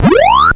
eat_ghost.wav